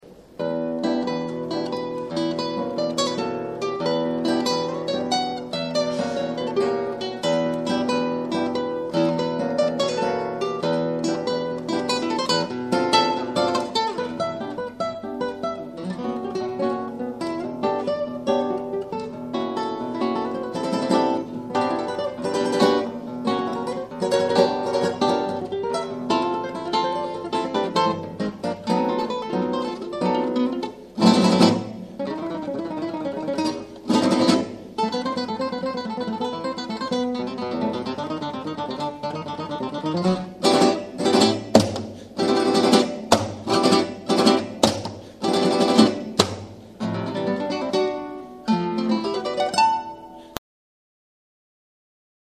They are mostly live extracts from public performances.
(live extract)
for two guitars